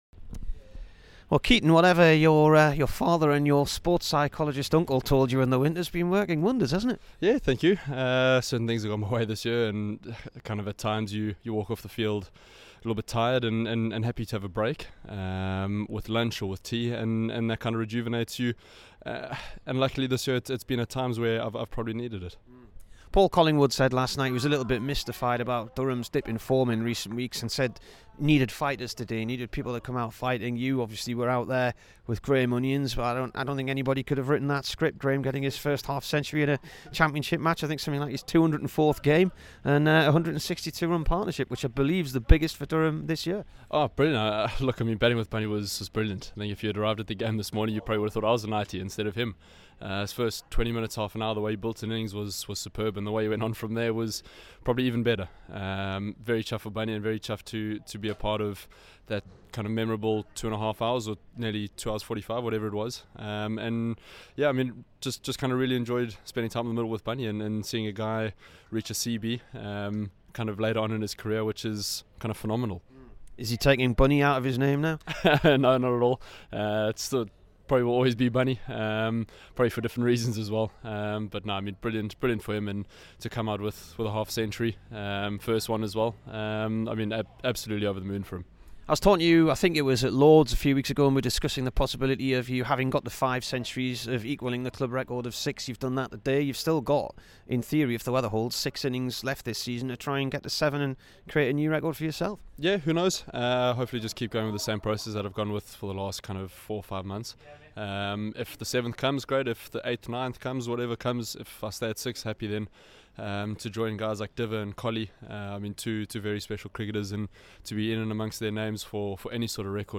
KEATON JENNINGS INTERVIEW